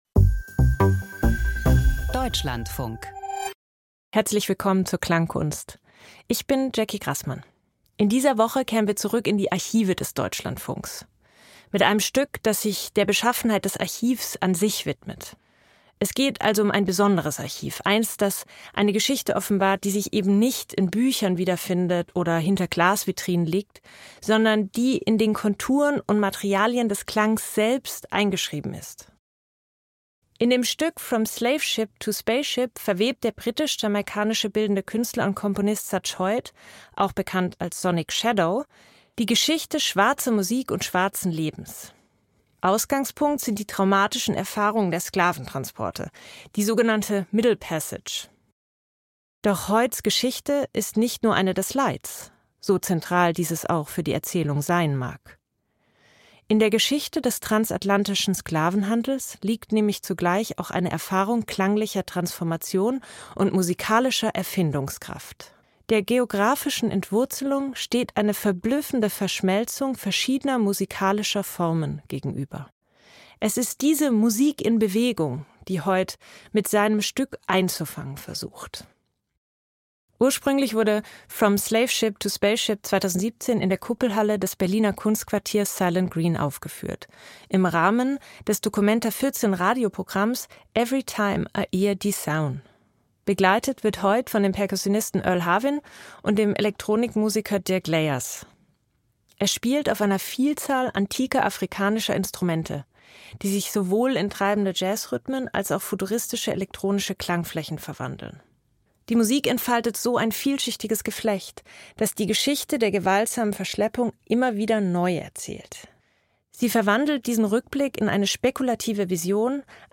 lässt afrikanische Instrumente erklingen